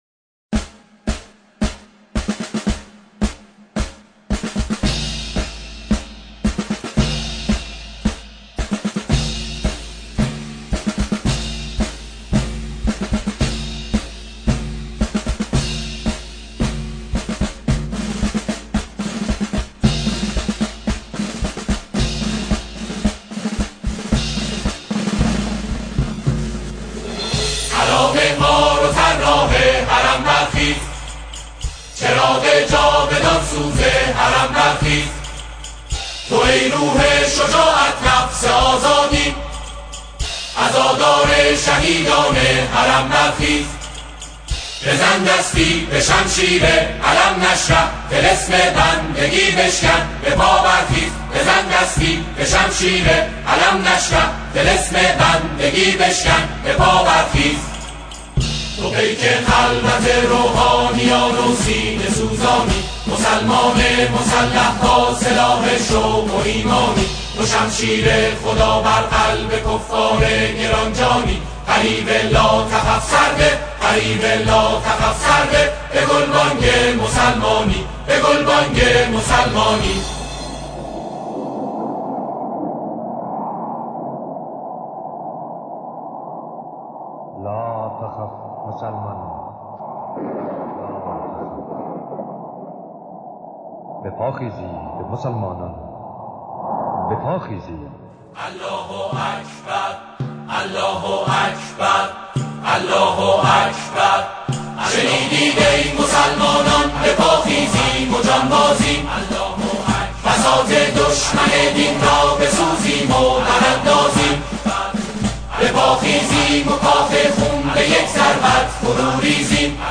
سرودهای دهه فجر